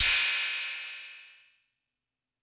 Techno Cymbal 01.wav